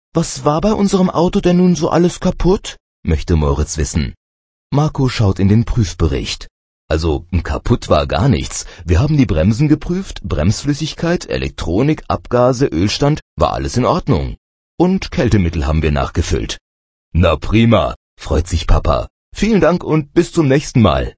Hörbuch Seite 10